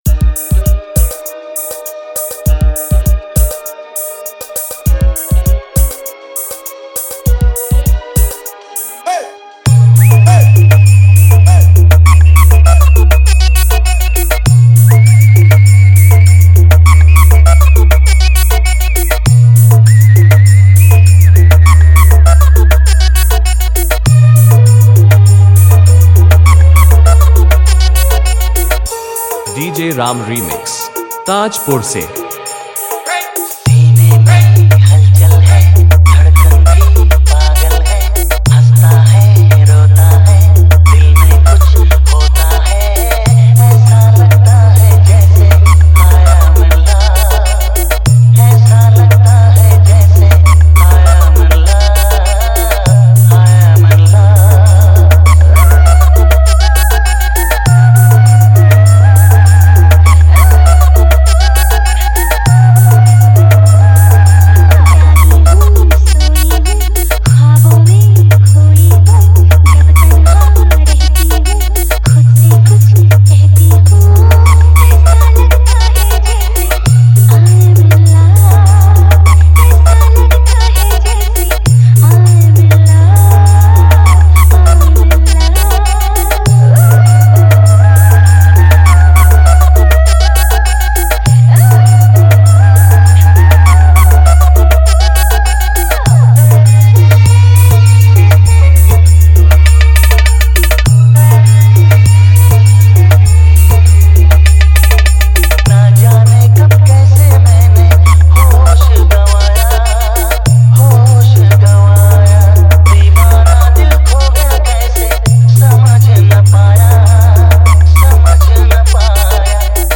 Humming Bass